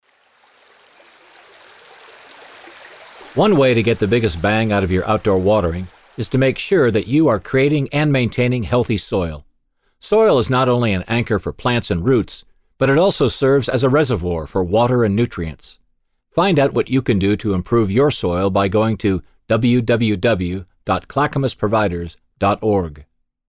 The audio PSAs (Public Service Announcements) below are designed to inform and educate our customers on ways to be more efficient with their outdoor water use throughout the summer and how to turn down and shut off outdoor watering in the late summer in time for the fall fish migration in the Clackamas River.